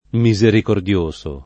misericordioso [ mi @ erikord L1S o ] agg.